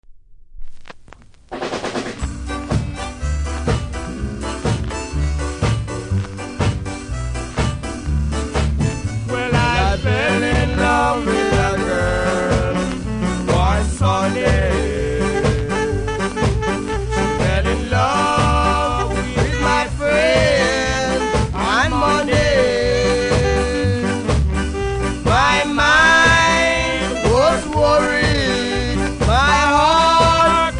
序盤プレス起因でノイズ感じます。